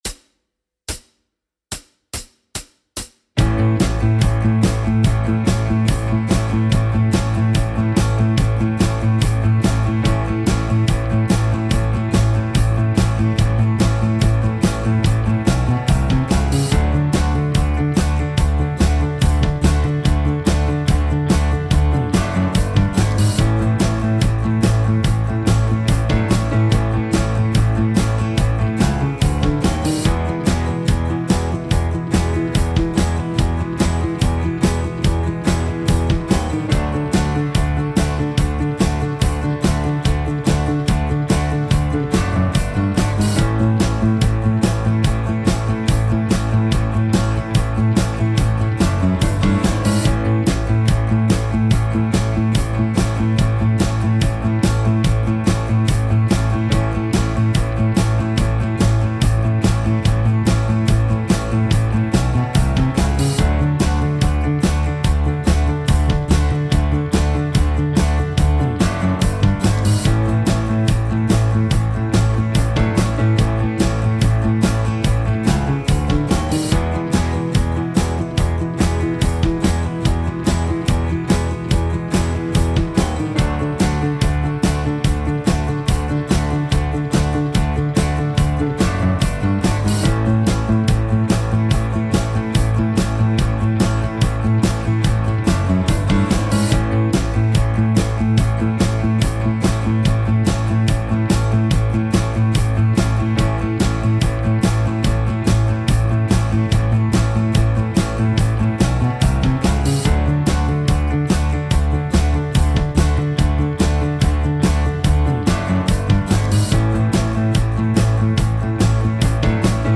Outlaw Country Jam Tracks